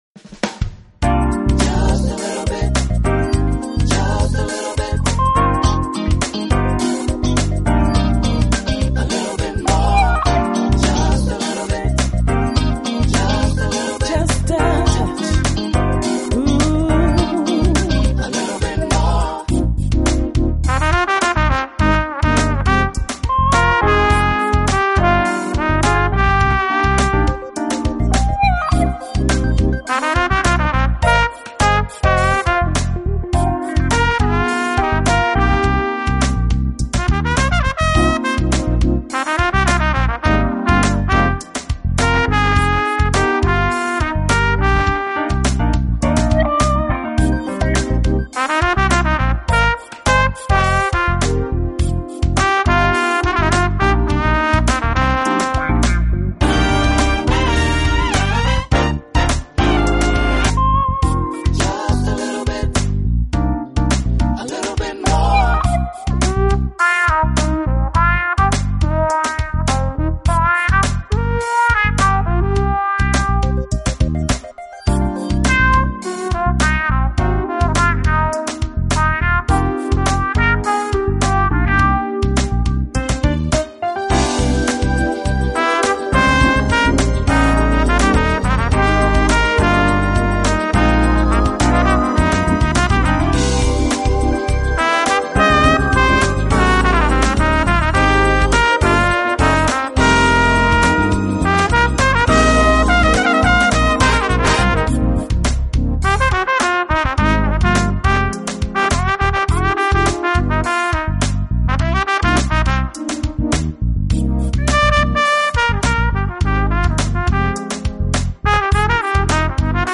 Genre: Jazz / Smooth Jazz